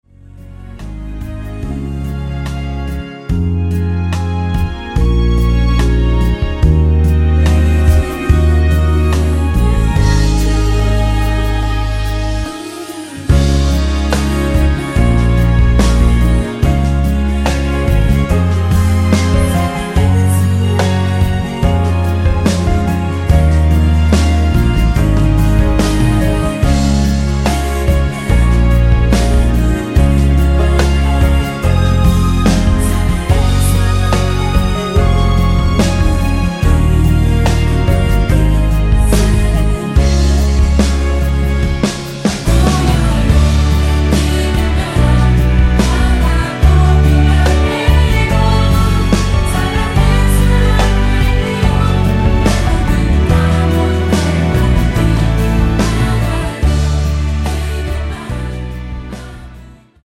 (-2) 내린 MR
코러스 포함된 MR 입니다.
앞부분30초, 뒷부분30초씩 편집해서 올려 드리고 있습니다.
중간에 음이 끈어지고 다시 나오는 이유는